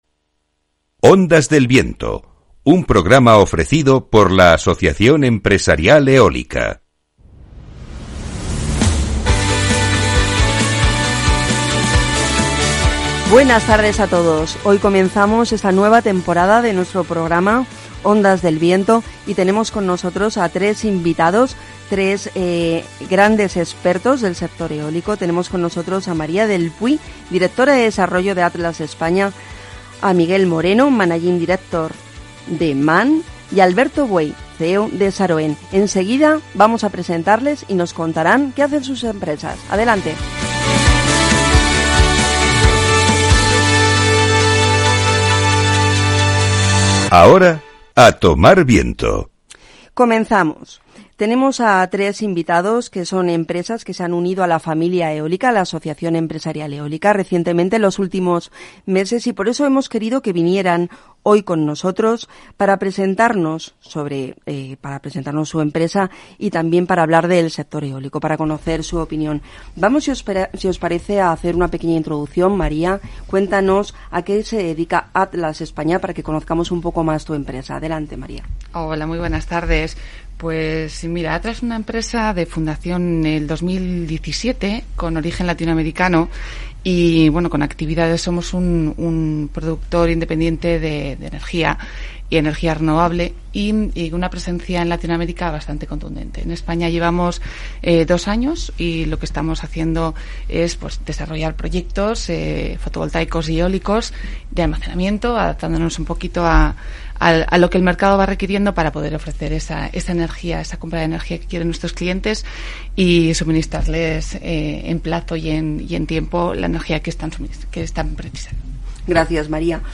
En nuestro regreso a Ondas del Viento, tras las vacaciones de verano, hemos estado en el estudio de Capital Radio con los representantes de 3 nuevas empresas que se han asociado a AEE en este año: ATLAS ESPAÑA, MAN ENERGY y SAROEN.